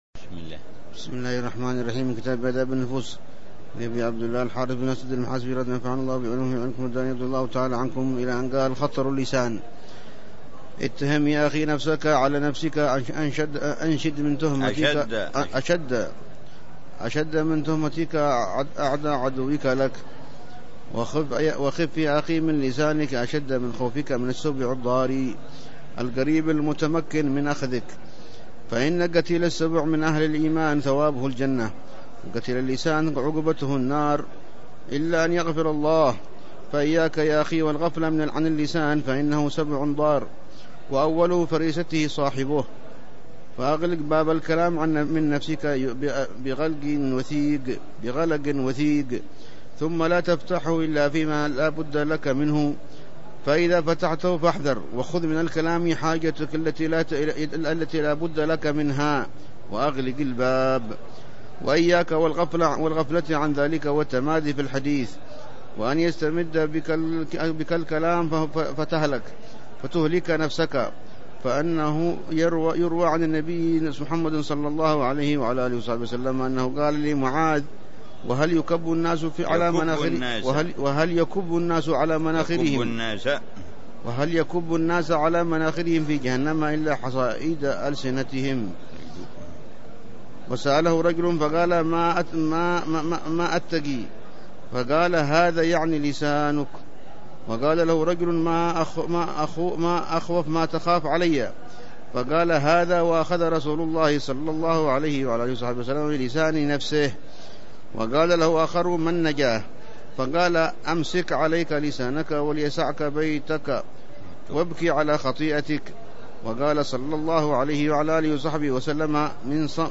الدرس الخامس من دروس الحبيب عمر بن حفيظ في شرح كتاب آداب النفوس للإمام أبي عبد الله الحارث المحاسبي، يتحدث عن أهمية تهذيب النفس وتزكيتها والنه